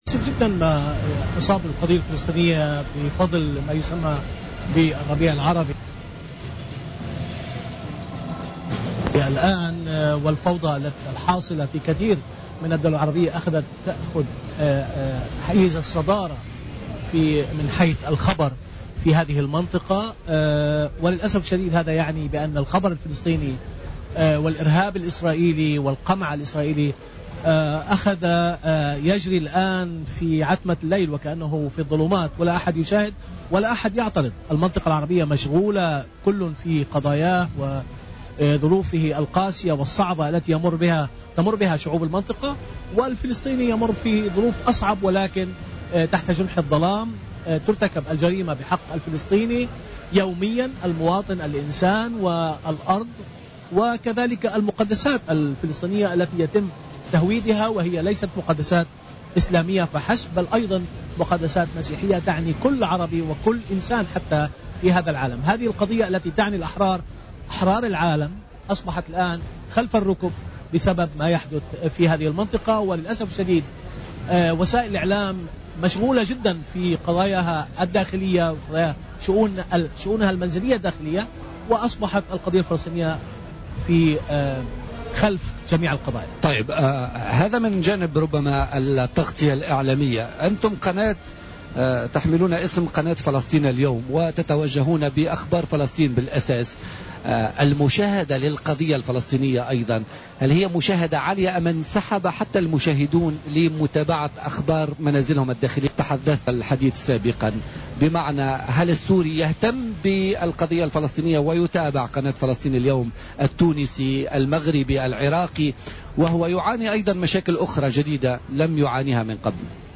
وأضاف على هامش فعاليات المهرجان العربي للإذاعة والتلفزيون بالحمامات أن دول المنطقة العربية ووسائل الإعلام أصبحت منشغلة كل في قضاياه ومشاغله، وهو ما أعطى فرصة للعدو الصهيوني أن يفعل ما يريد، ويمارس إرهابه وقمعه.